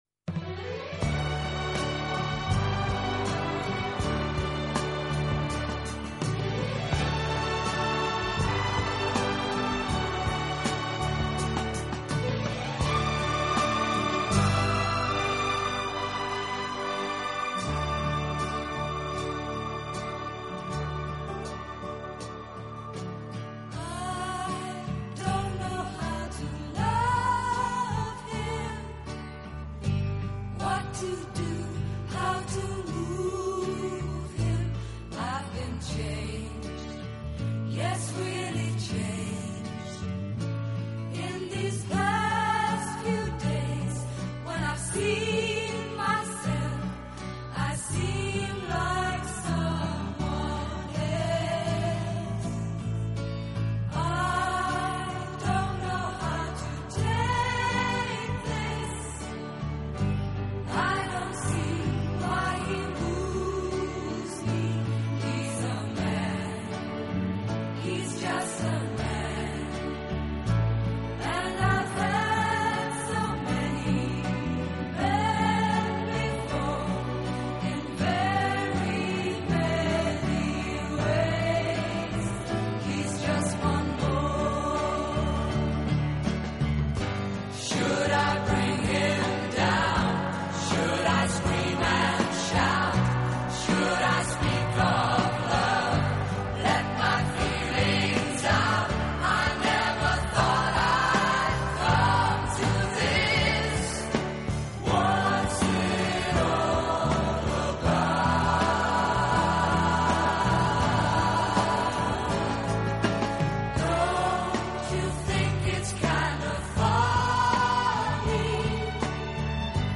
此外，这个乐队还配置了一支训练有素，和声优美的伴唱合唱队。